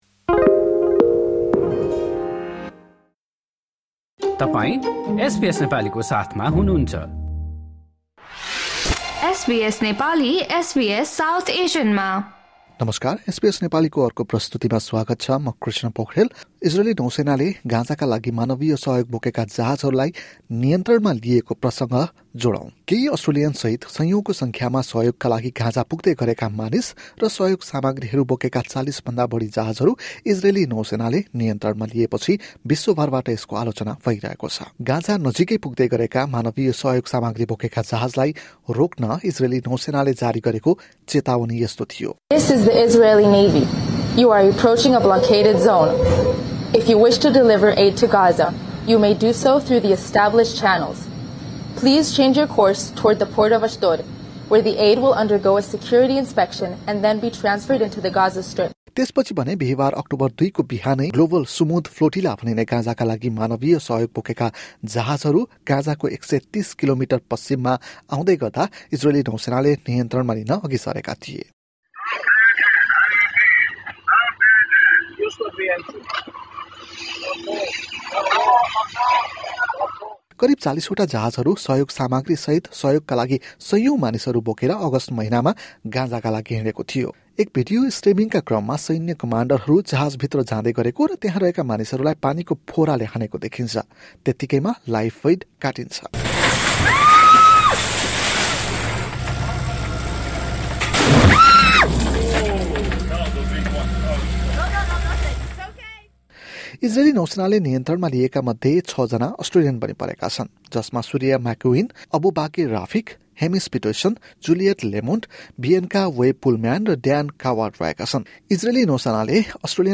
केही अस्ट्रेलियनहरू सहित सयौँको सङ्ख्यामा सहयोगका लागि भन्दै गाजातर्फ लागेका, मानिस र सहयोग सामाग्रीहरु बोकेका ४० भन्दा बढी जहाजहरू इजरेली नौसेनाले नियन्त्रणमा लिएपछि विश्वभरबाट यसको आलोचना भइरहेको छ। एक रिपोर्ट।